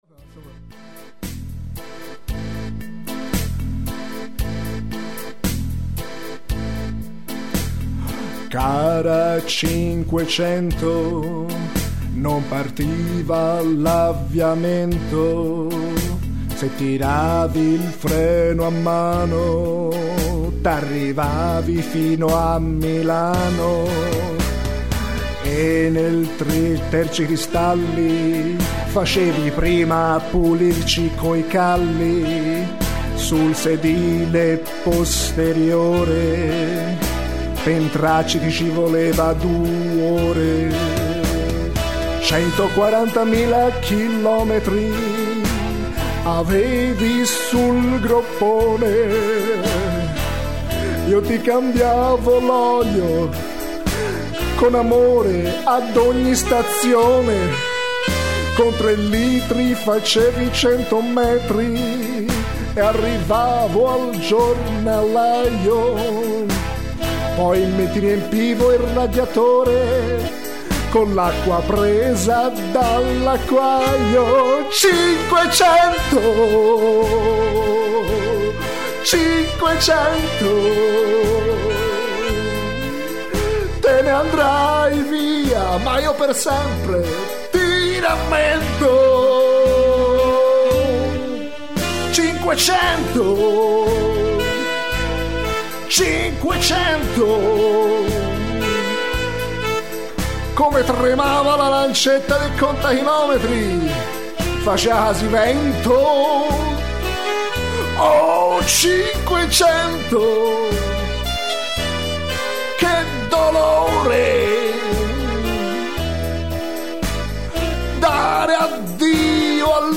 Un requiem di addio